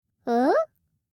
알림음 8_HobbitOh4.mp3